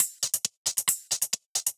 Index of /musicradar/ultimate-hihat-samples/135bpm
UHH_ElectroHatB_135-05.wav